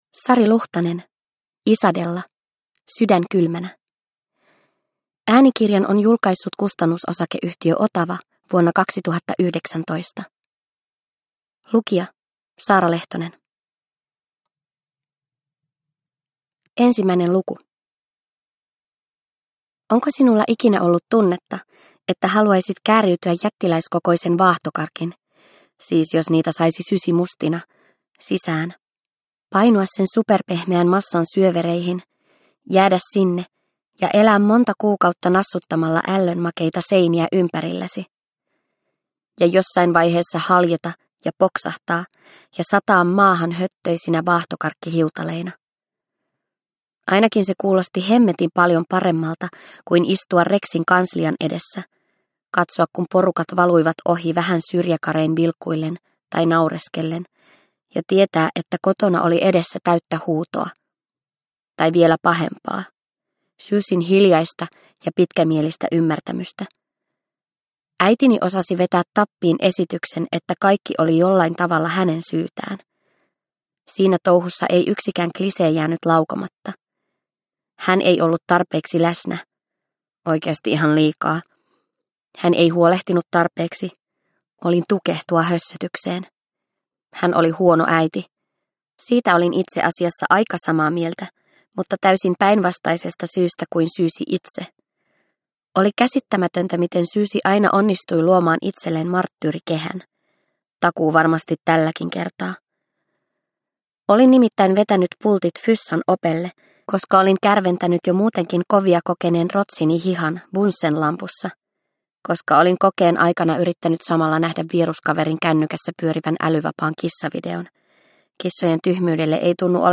Isadella - Sydän kylmänä – Ljudbok – Laddas ner